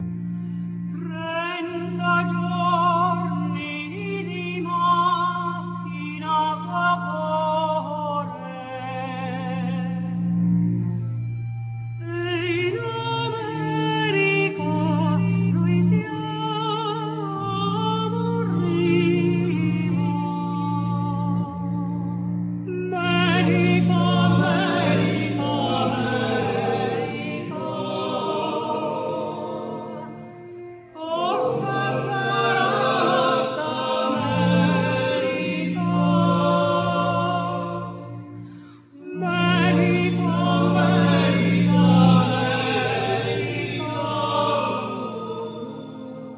Vocal
Violin
Cello
Piano
Chorus
Percussions
Keyboards
Tambura,Violin,Mandolin
Flute,Clarinetto,Percussions
Harmonica
Guitar